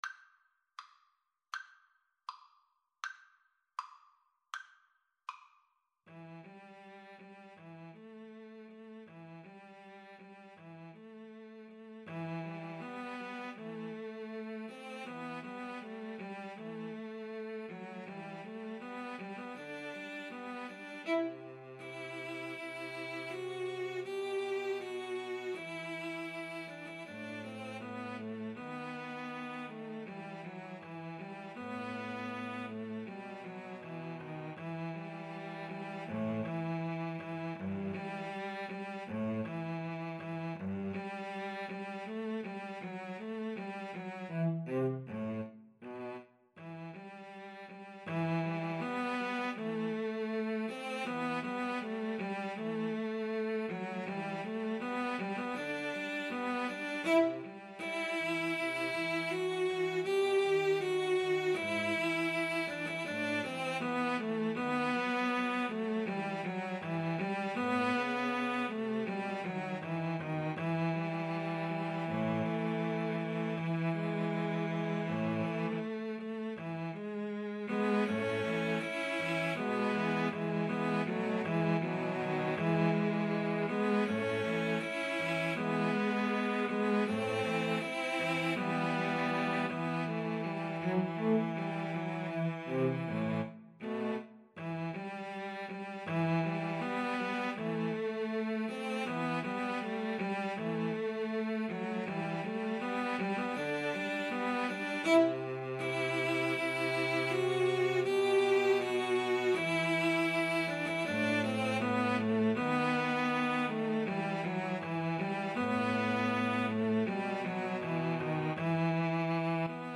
E minor (Sounding Pitch) (View more E minor Music for Cello Trio )
~ = 100 Andante
Classical (View more Classical Cello Trio Music)